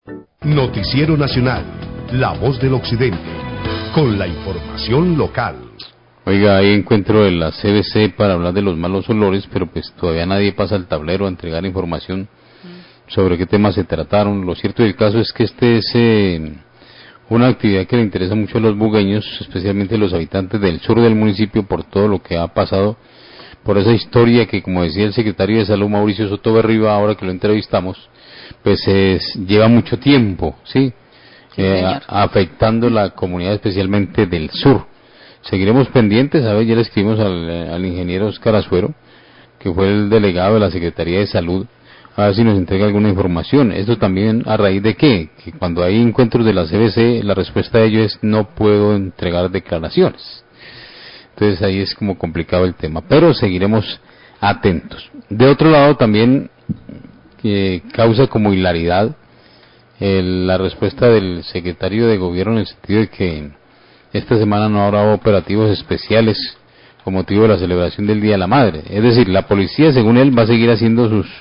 Radio
Periodista comenta sobre encuentro de la Secretaría de Salud de Buga en la CVC para hablar del tema de los malos olores que se siente en la cudad pero nadie da información al respecto. Agrega que cuando se pregunta a funcionarios de CVC, responden que no pueden dar declaraciones sobre el tema.